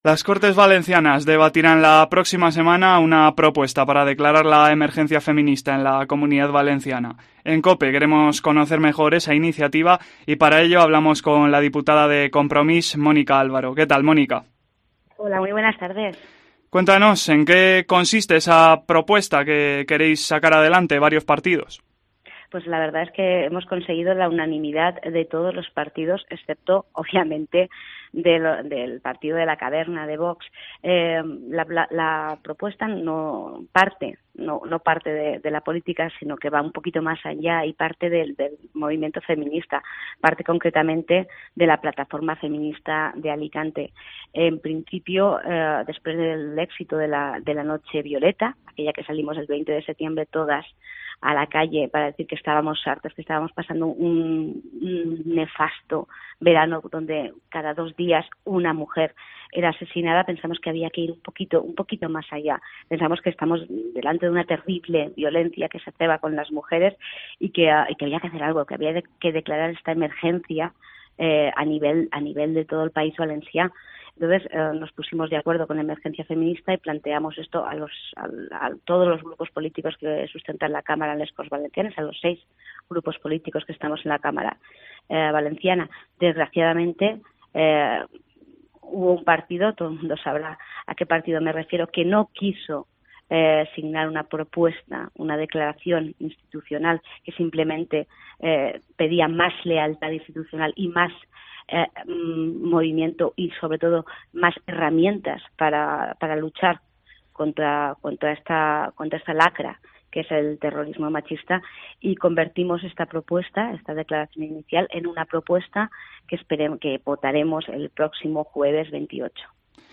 Su diputada Mònica Àlvaro habla con COPE sobre la propuesta que se debatirá en las Cortes valencianas el próximo 28 de noviembre